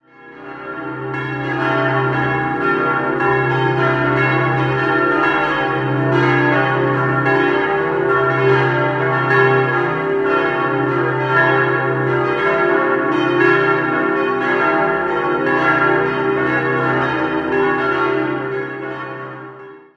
5-stimmiges ausgefülltes SalveRegina-Geläute: c'-d'-e'-g'-a' Die Glocken wurden 1947 von Petit&Edelbrock in Gescher (Westfalen) gegossen und bilden zusammen das tontiefste Geläut des Landkreises Regensburg.